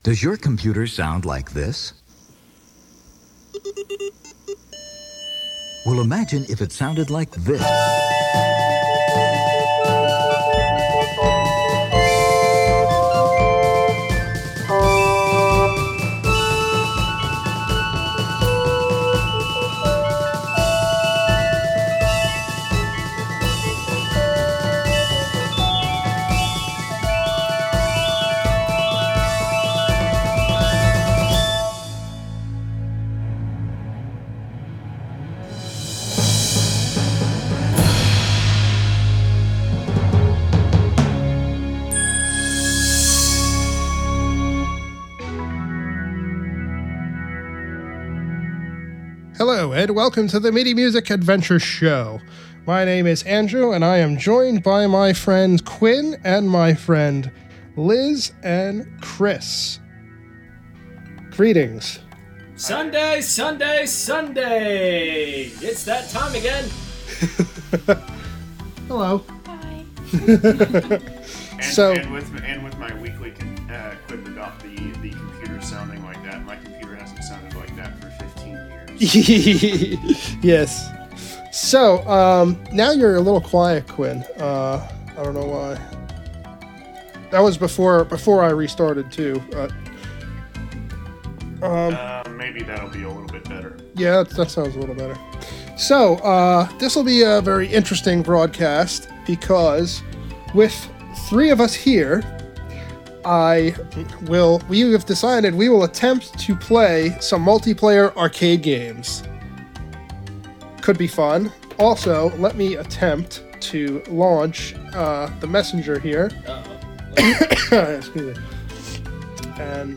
I used my Roland CS-10em Binaural Microphone’s to record the sounds from the room and all the arcade controller buttony goodness!